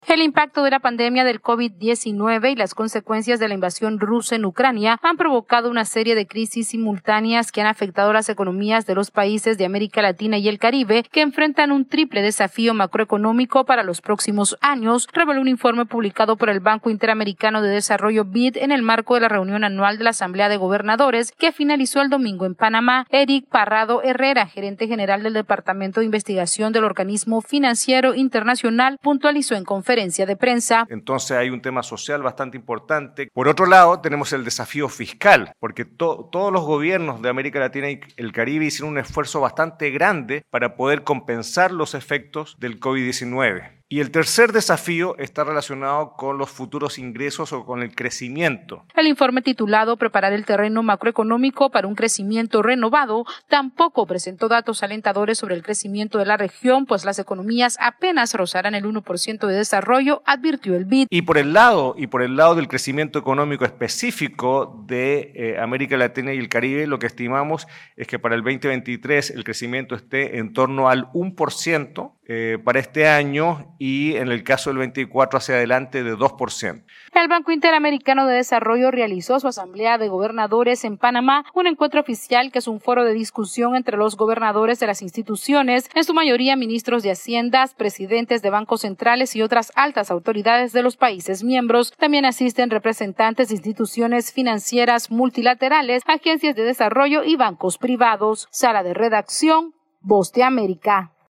AudioNoticias
El Banco Interamericano de Desarrollo advirtió que las economías de América Latina y el Caribe crecerán apenas un 1% durante 2023, dejando a la región expuesta a un triple desafío macroeconómico. Esta es una actualización desde la Sala de Redacción de la Voz de América